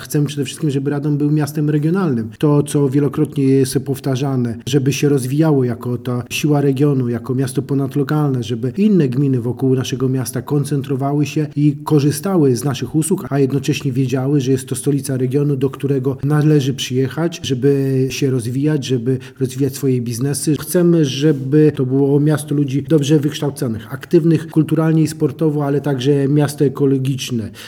O szczegółach mówi wiceprezydent Radomia, Jerzy Zawodnik :